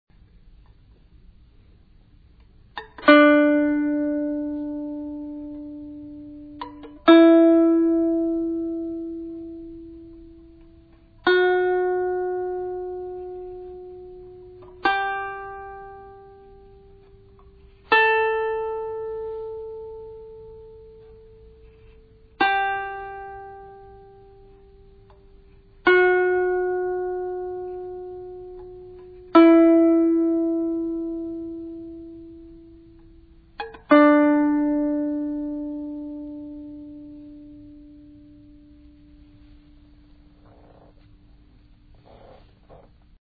lKanun ile icra ettiği
Rast dizisi ve
rast_dizi5li-k.mp3